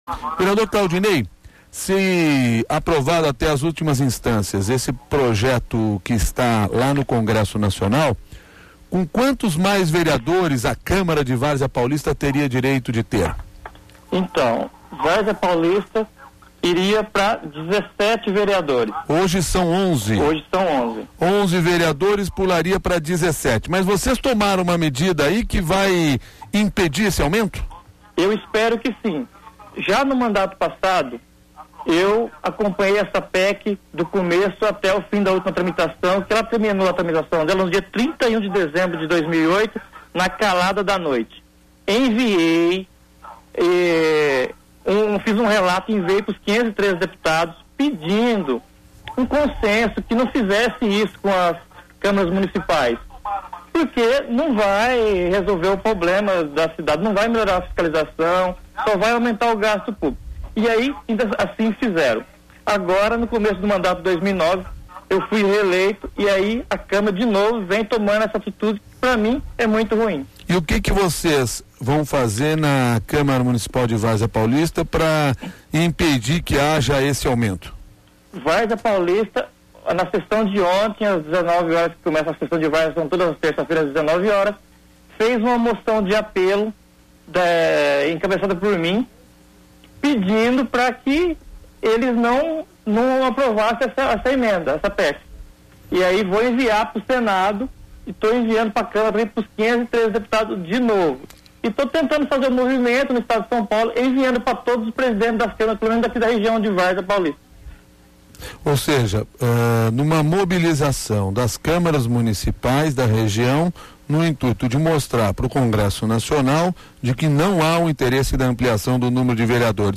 Ouça a entrevista do vereador Claudinei de Lima Lumes, de Várzea Paulista